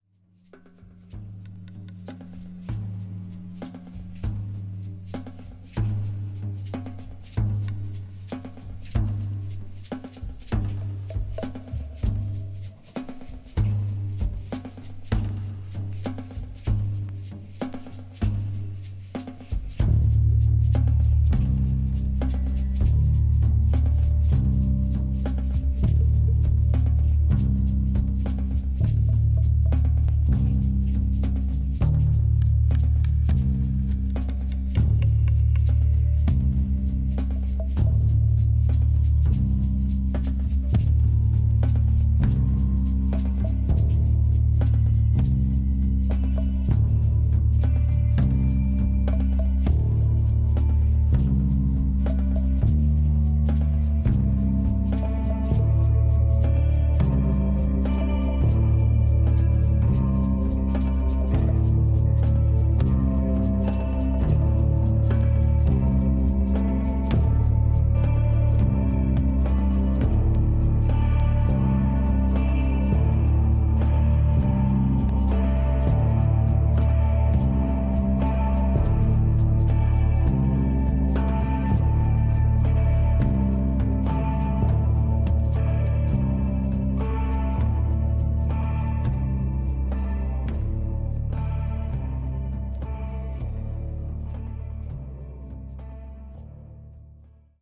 Tags: Rock , Psicodelico , Colombia , Bogotá